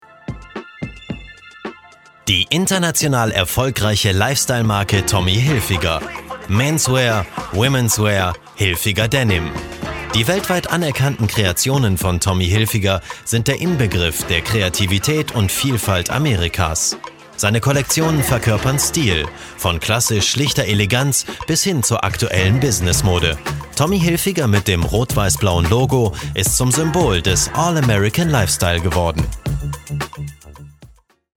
Male
Authoritative, Confident, Friendly, Natural
A young, bright and fresh voice, that's warm, welcoming and full of character.
Microphone: Neumann TLM 103